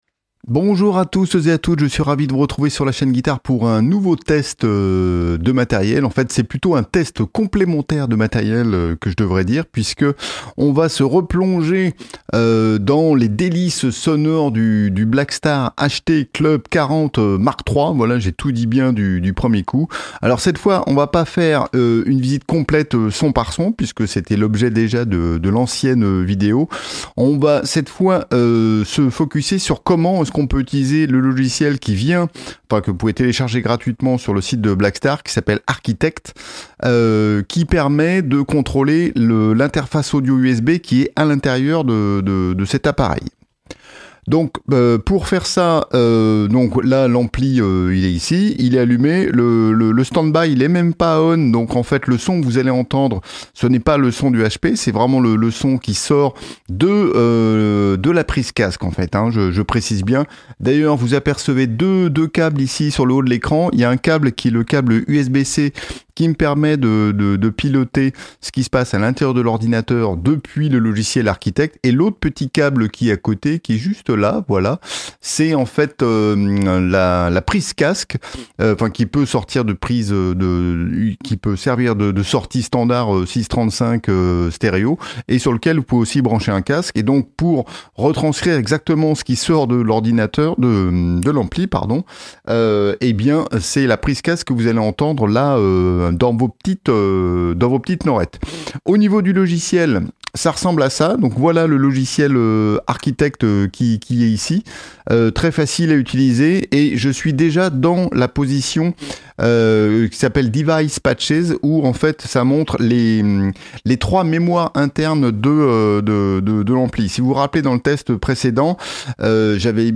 Le Blackstar HT Club 40 MK III est un excellent ampli à lampes qui est idéalement équipé pour la prise de son, la preuve dans ce test !
Test-ampli-Blackstar-HT-Club-USB.mp3